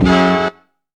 KING HIT.wav